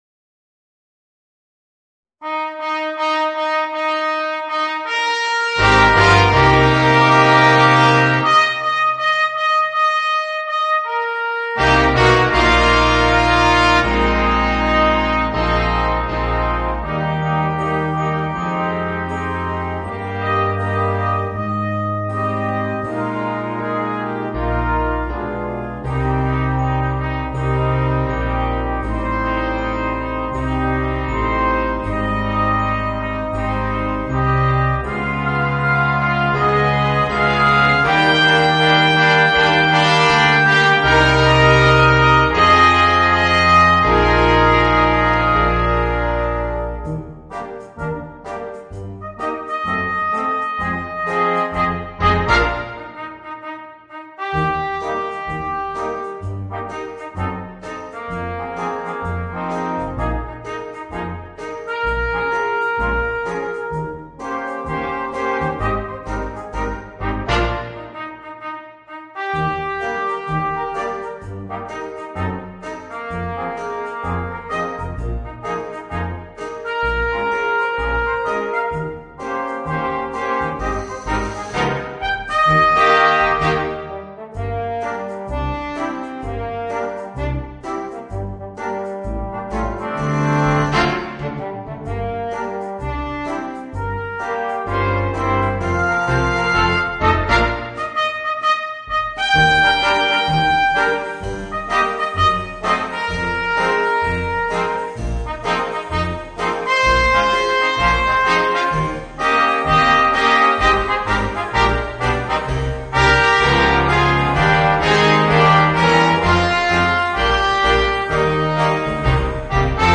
Voicing: 5 - Part Ensemble and Rhythm Section